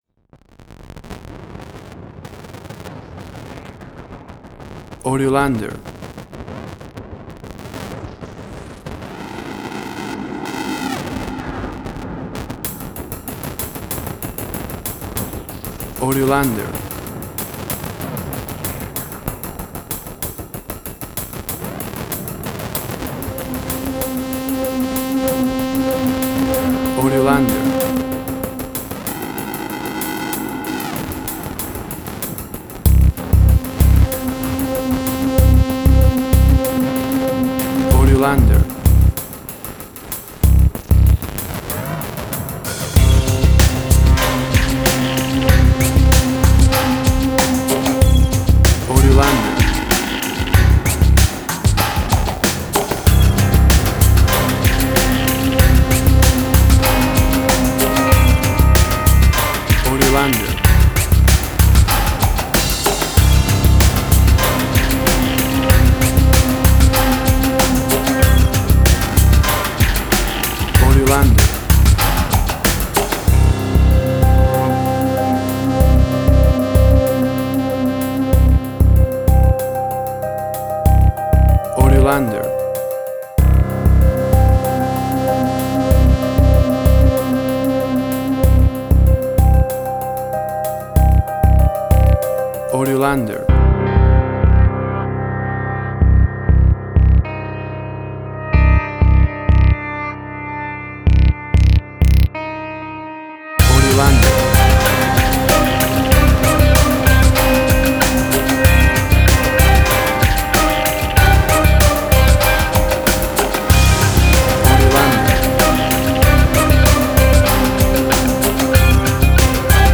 Suspense, Drama, Quirky, Emotional.
Tempo (BPM): 96